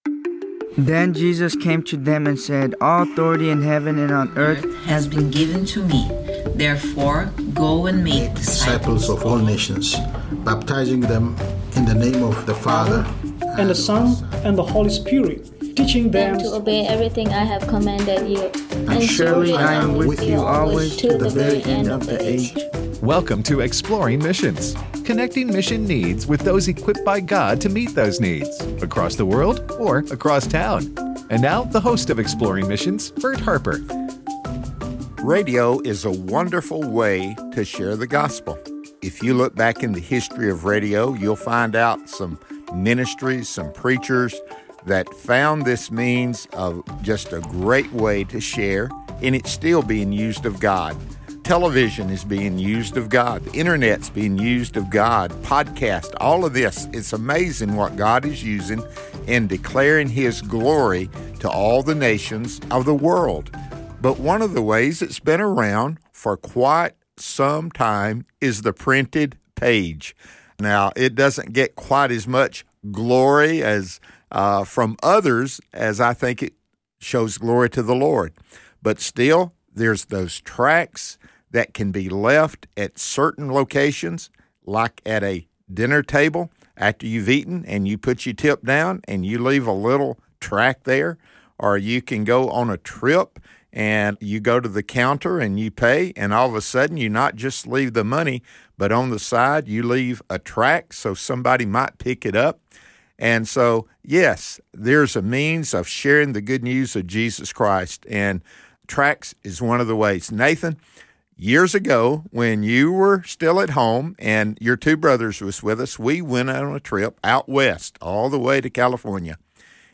The Global Mission Field: A Conversation